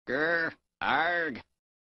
Play, download and share Grr Argh original sound button!!!!
grr-argh.mp3